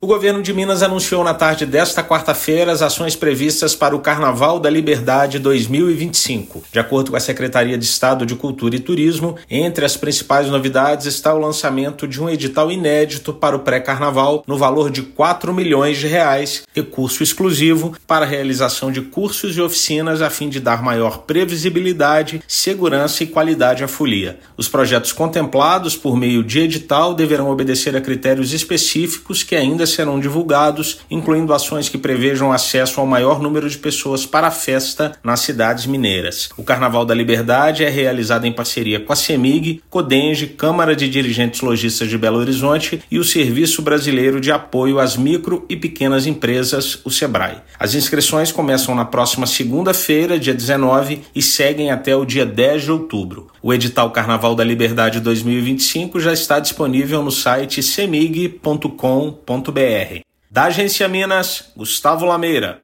Edital para o pré-Carnaval, em parceria com a Companhia Energética de Minas Gerais (Cemig), e iniciativas de promoção e rodadas de negócios na Travel Next Minas são destaques. Ouça matéria de rádio.